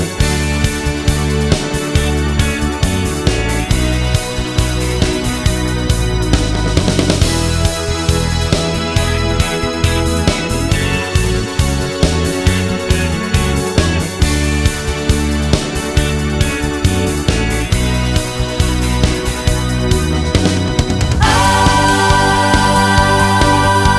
No Guitars Pop (1980s) 3:58 Buy £1.50